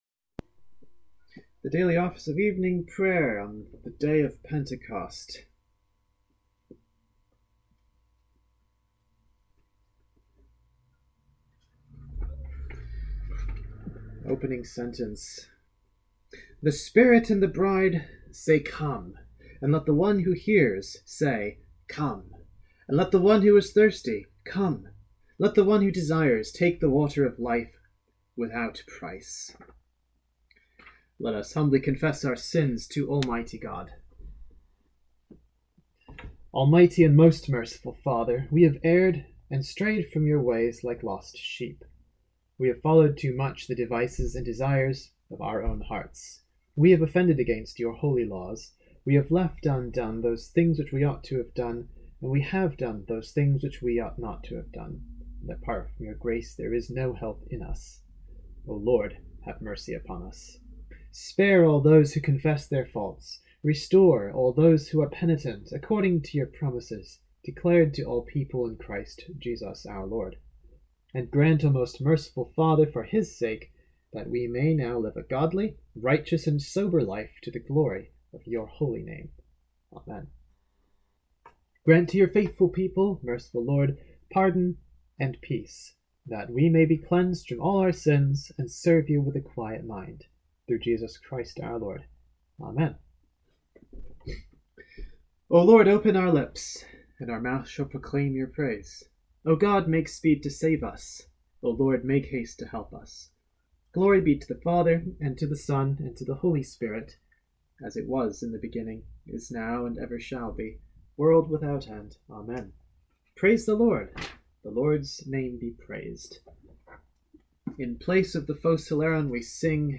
Here’s a little surprise, or bonus, for this evening: I’ve recorded the Daily Office of Evening Prayer for Pentecost evening!
pentecost-evening-prayer-2020.mp3